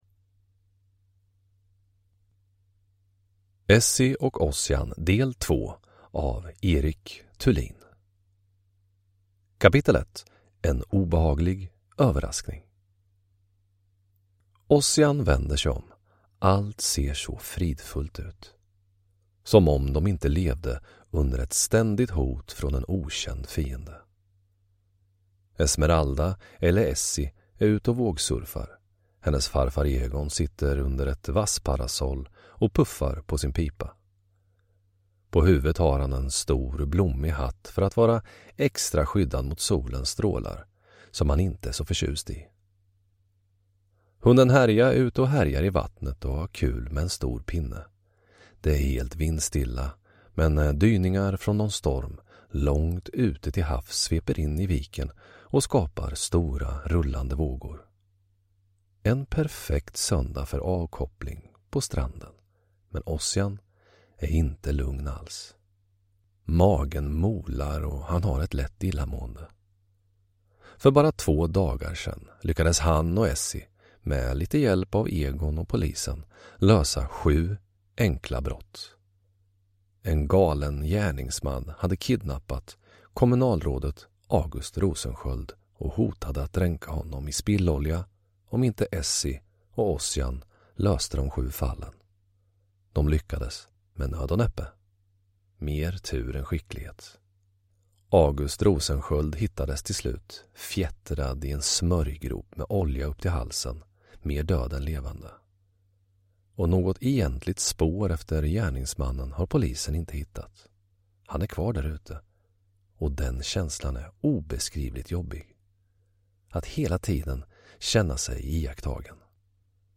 Essi och Ozzian – Del 2 – Ljudbok – Laddas ner